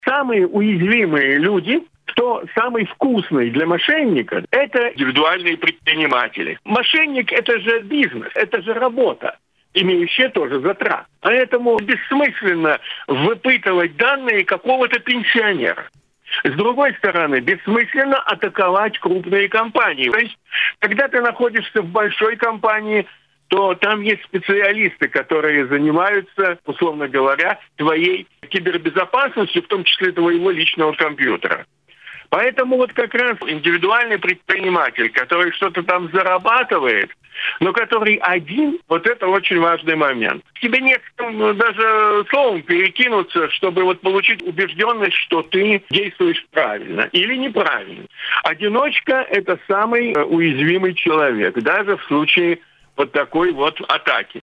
Наиболее уязвимые к действиям мошенников люди – отнюдь не пенсионеры или работники крупных компаний. Об этом в эфире радио Baltkom рассказал ученый